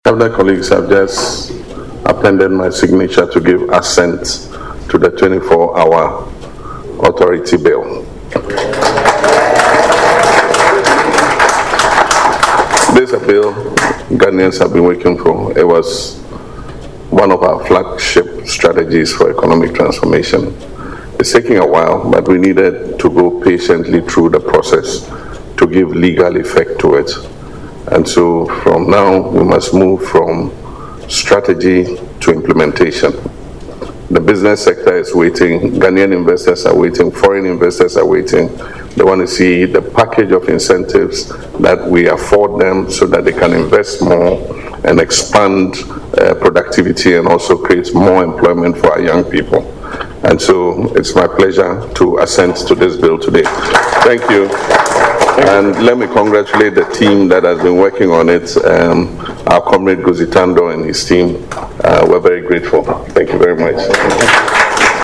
ACCRA, Ghana, 19 February: President John Dramani Mahama has signed the 24-Hour Economy Authority Bill into law at the Presidency, paving the way for the full implementation of the government’s flagship economic policy.
LISTEN TO PRESIDENT MAHAMA IN THE AUDIO BELOW: